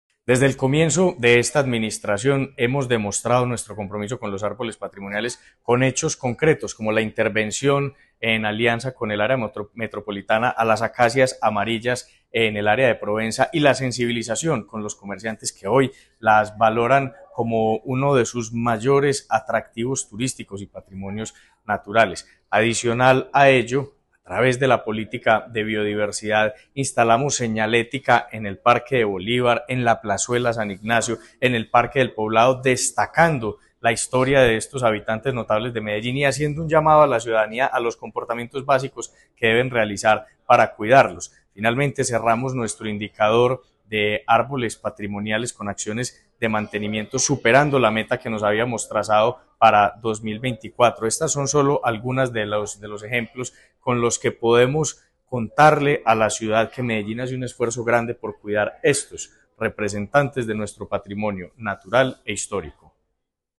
Audio Declaraciones del subsecretario de Recursos Naturales, Esteban Jaramillo Ruiz La Administración Distrital ha identificado 662 árboles y palmas patrimoniales mediante el Decreto 598 de 2019, que los reconoce como patrimonio natural y cultural de la ciudad.
Declaraciones-del-subsecretario-de-Recursos-Naturales-Esteban-Jaramillo-Ruiz.mp3